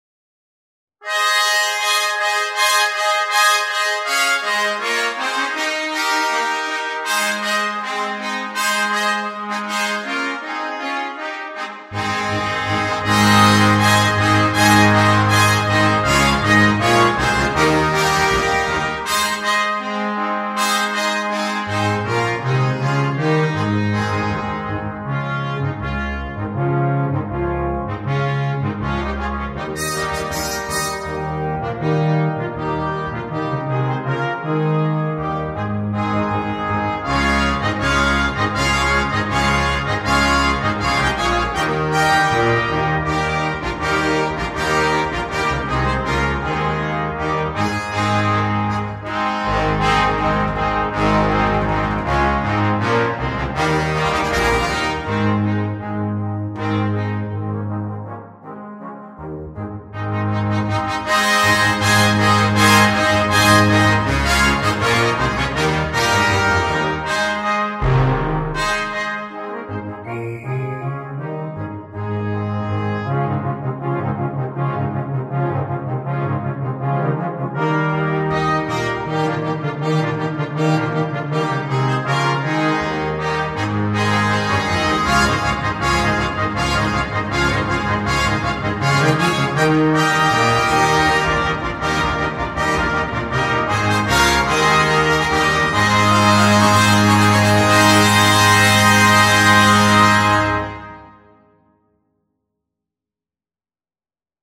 2. Ensamble de metales
10 jugadores de metal
Clásicas